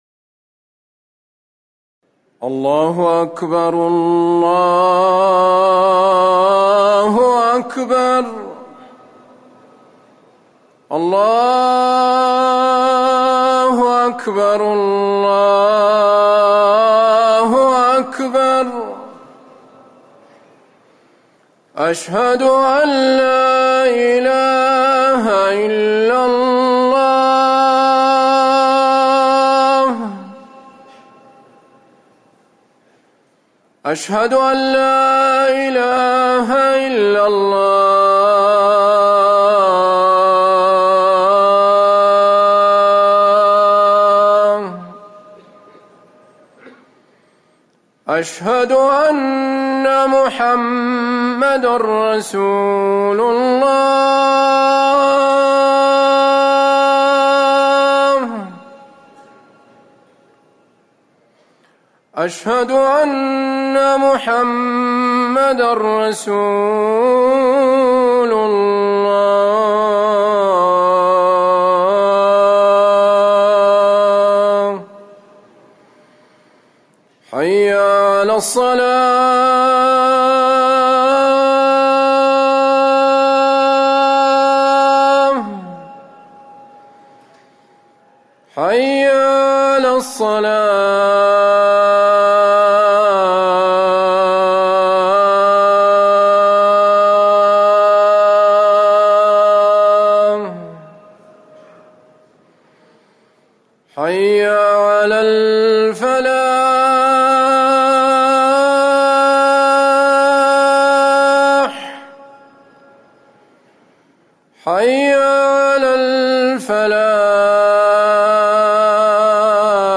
أذان المغرب
تاريخ النشر ٢٦ محرم ١٤٤١ هـ المكان: المسجد النبوي الشيخ